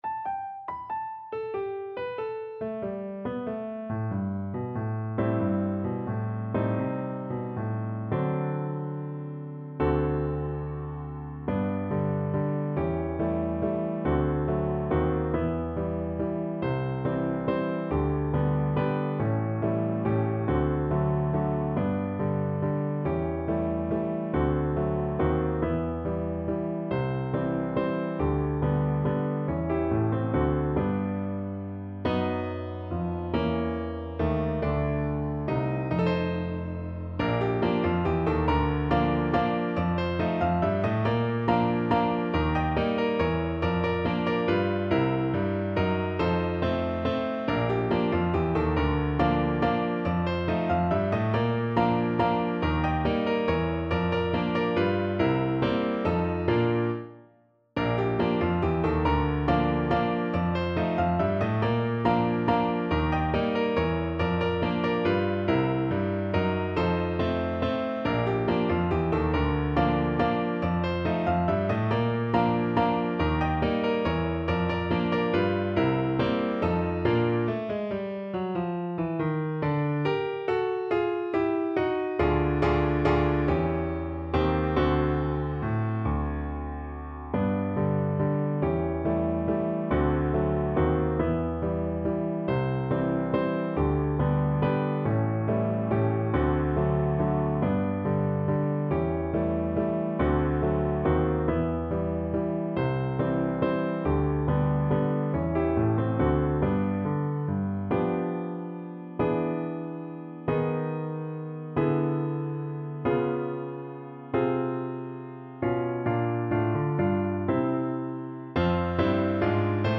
Valse Tempo a tempo. = 140
3/4 (View more 3/4 Music)
Jazz (View more Jazz Recorder Music)